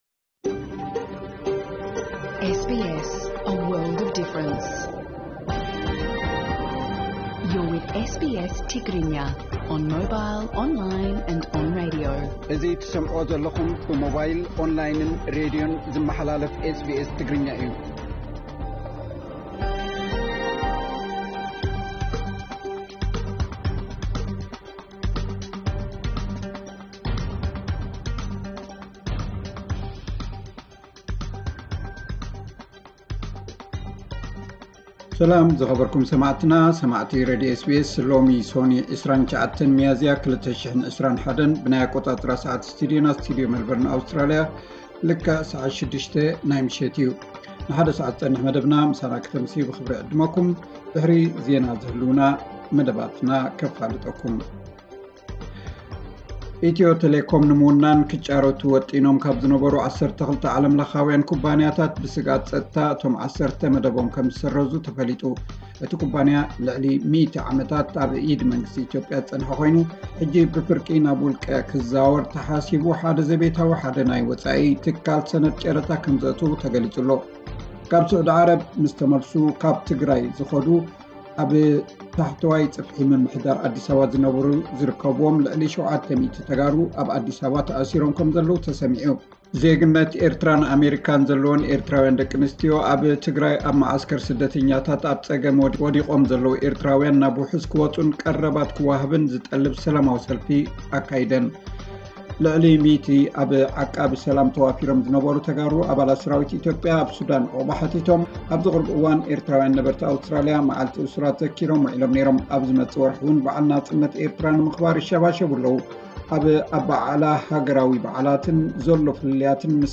ዕለታዊ ዜና 29 ሚያዚያ 2021 SBS ትግርኛ